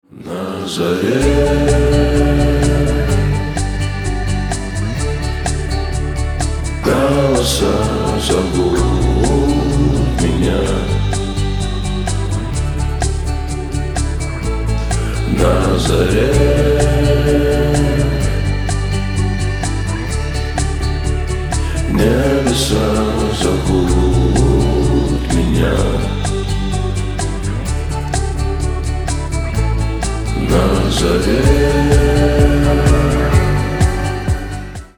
Категория: Русские рингтоныРомантические рингтоны